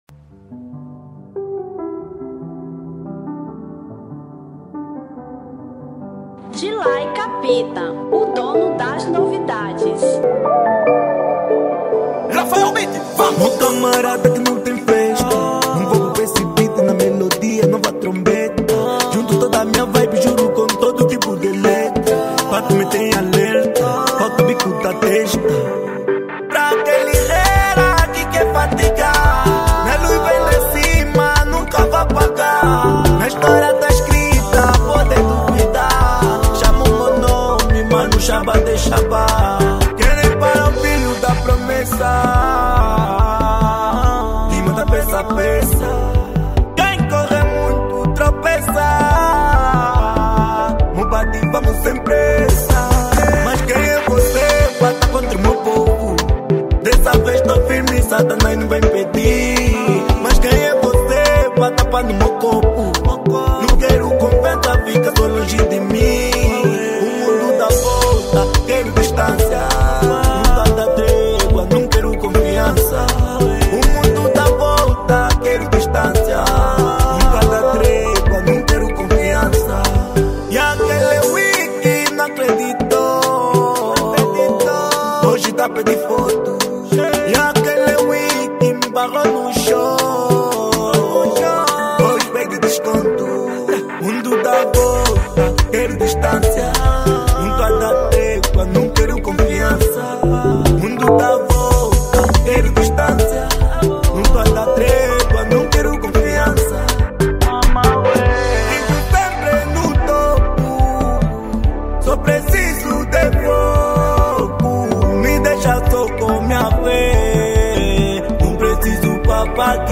Kuduro 2024